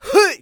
xys发力7.wav 0:00.00 0:00.43 xys发力7.wav WAV · 37 KB · 單聲道 (1ch) 下载文件 本站所有音效均采用 CC0 授权 ，可免费用于商业与个人项目，无需署名。
人声采集素材